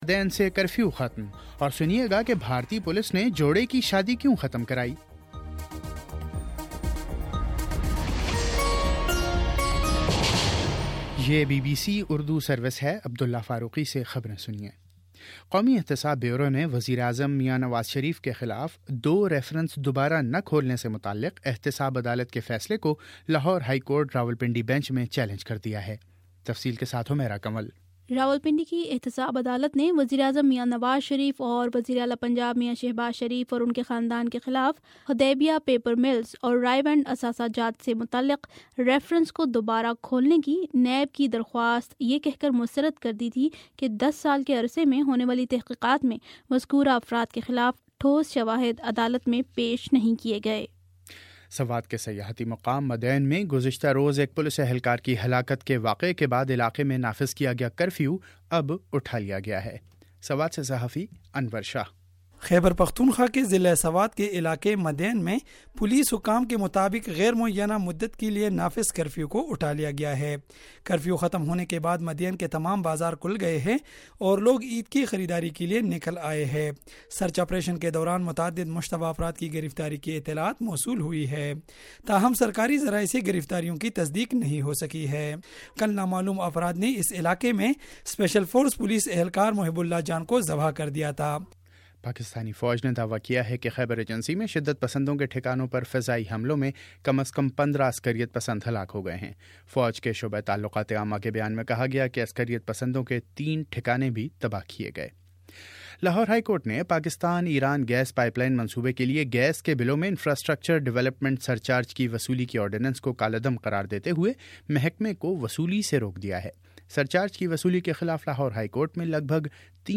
اکتوبر03 : شام چھ بجے کا نیوز بُلیٹن